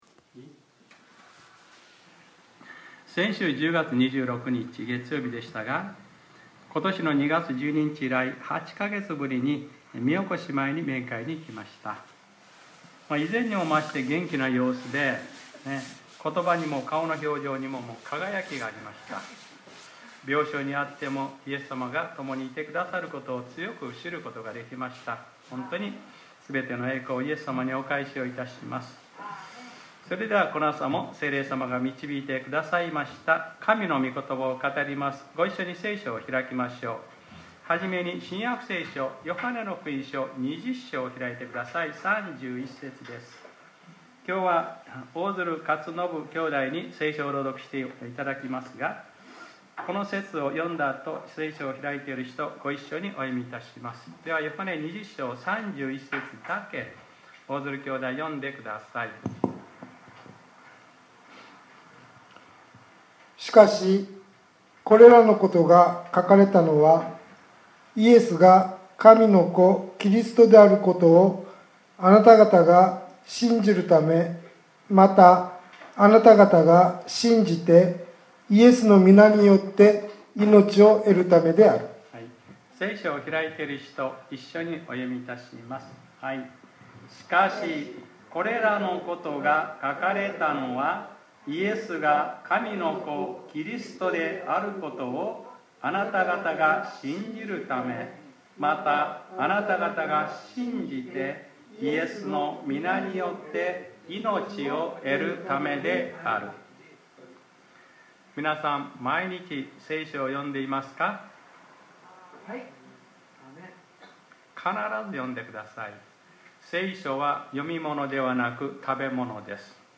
2020年11月01日（日）礼拝説教『あの罪は私にあるのです』